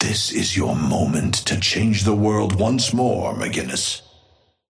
Amber Hand voice line - This is your moment to change the world once more, McGinnis.
Patron_male_ally_forge_start_04.mp3